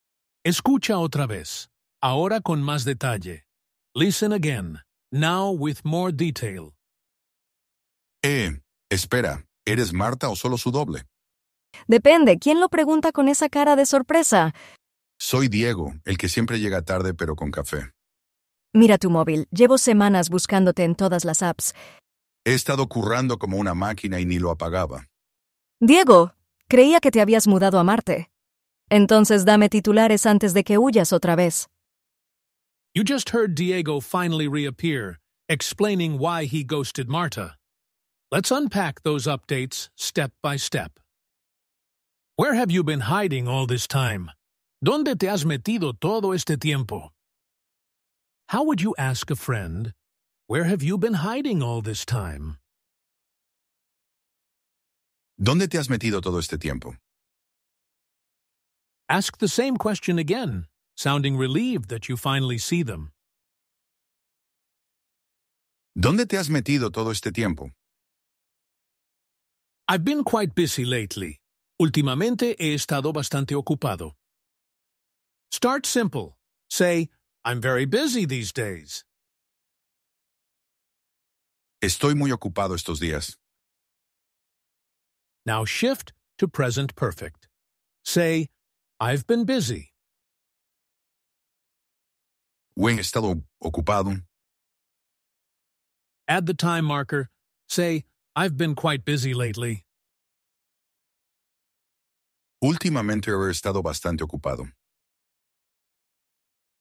Same method you already trust—anticipation, graduated interval recall, Castilian voices—but stretched into B1/B2 territory with richer dialogue, modern Spain slang, and a touch of humor that makes 14 hours of study bearable.
Rendered with ElevenLabs voices.
You hear the full exchange cold, then we slow it down, prompt you to retell it, and recycle the same joke later in the lesson for recall.
Recorded in Madrid with vosotros, ceceo, and vocabulary like móvil, ordenador, vale, venga, tío/tía, currar, en plan, guay, pasta, flipar.